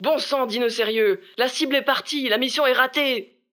VO_ALL_EVENT_Temps ecoule_01.ogg